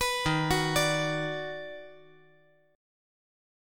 EbmM7#5 Chord
Listen to EbmM7#5 strummed